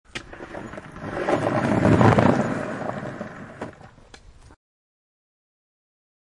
Download Skateboard sound effect for free.
Skateboard